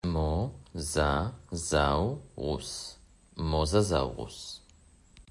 מו-זא-זאו-רוס